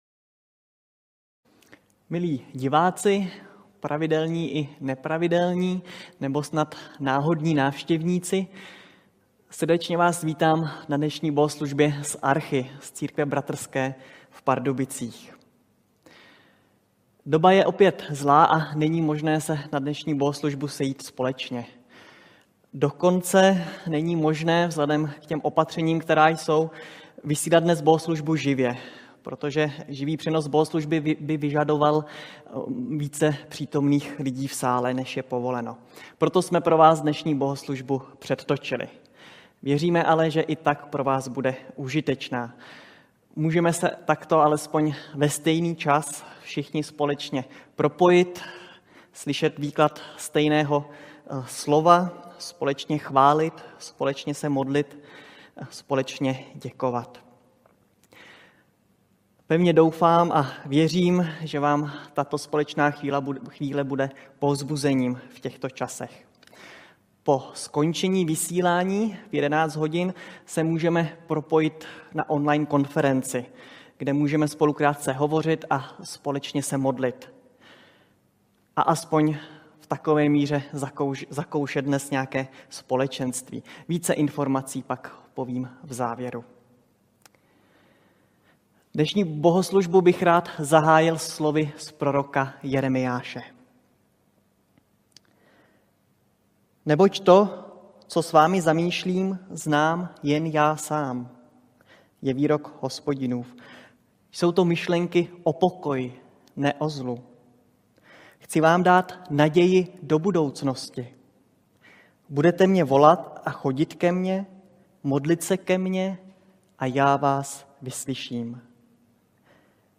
6. díl ze série kázání Ester (8.- 10. kap.)
Kategorie: Nedělní bohoslužby